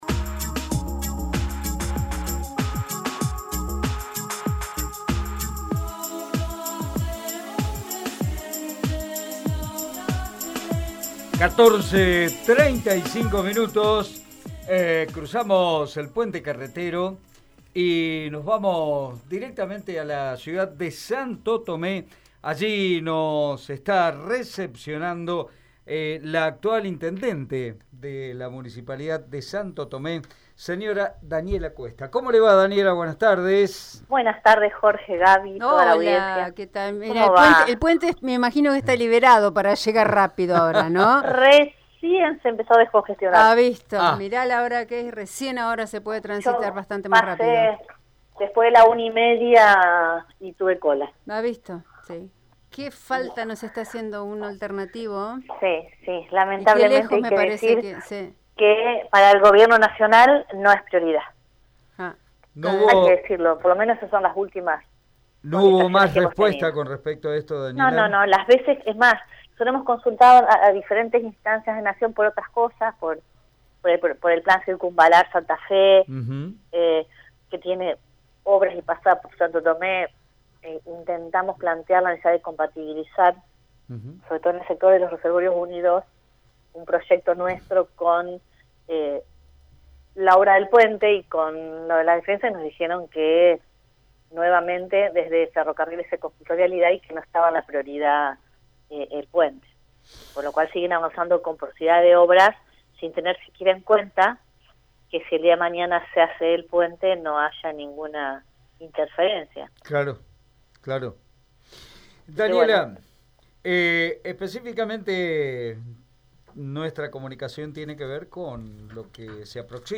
La intendenta santotomesina Daniela Qüesta habló este miércoles en los micrófonos de Radio EME. Y a la hora de hablar de Santo Tomé, es inevitable referirse al Puente Carretero que une dicha ciudad con Santa Fe, que sigue sumando años de reclamo en cuanto a las dificultades que ocasiona por la gran carga vehicular diaria y las complicaciones que genera esto en los vecinos de ambas ciudades.